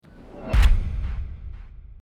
echo_portal.m4a